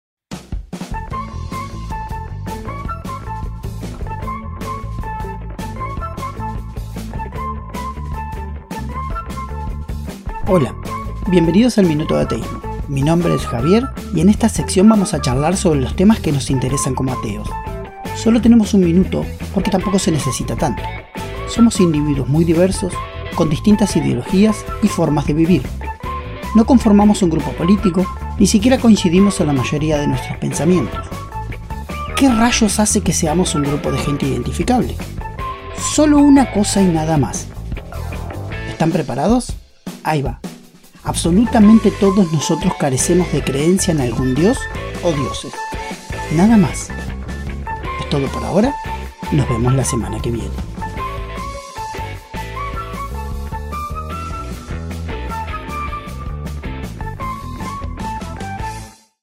Este minuto se emite todos los martes a las 22 por LT 11 y Radio Uner en el programa radial En La Víspera, producido por El Miércoles Comunicación y Cultura, Cooperativa de Trabajo Ltda.